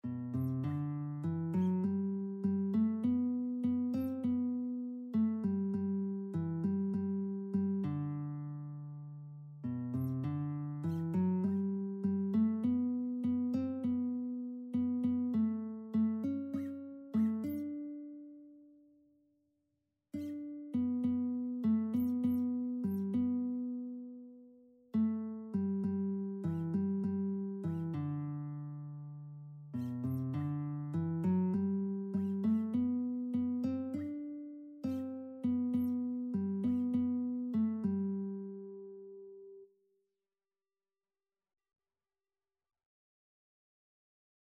Christian
4/4 (View more 4/4 Music)